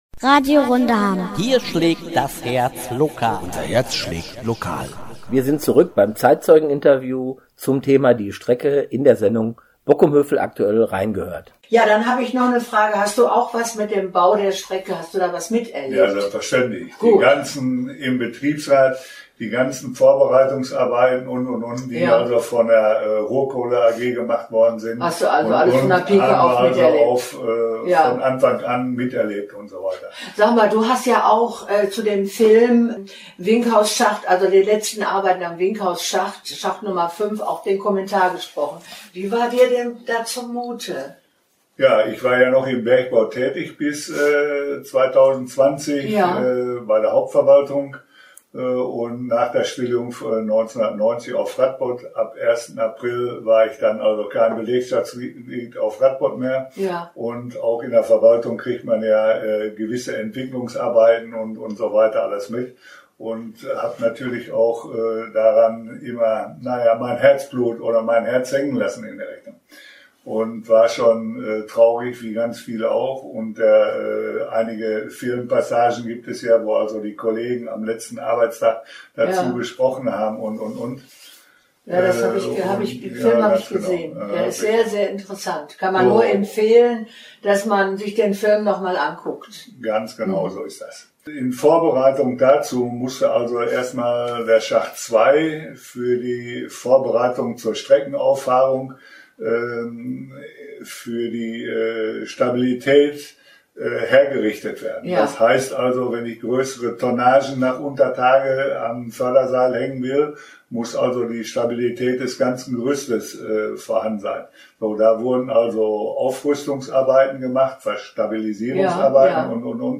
„Die Strecke“ – Zeitzeugen im Interview (Podcast verfügbar)